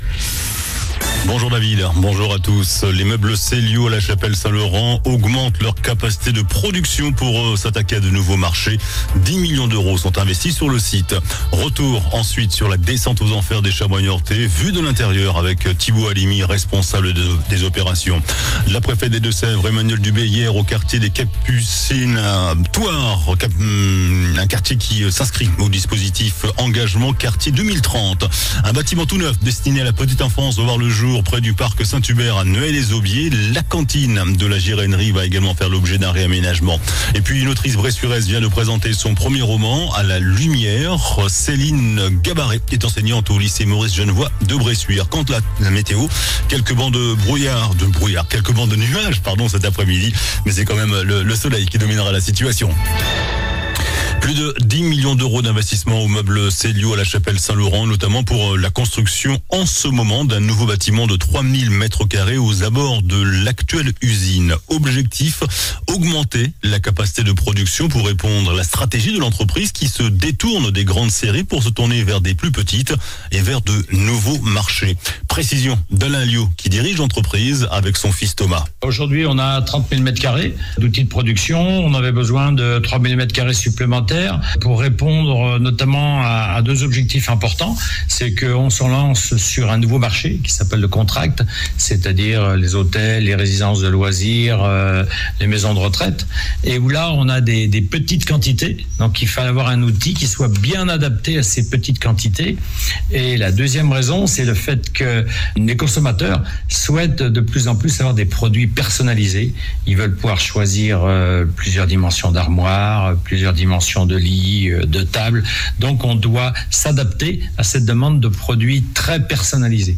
JOURNAL DU JEUDI 12 SEPTEMBRE ( MIDI )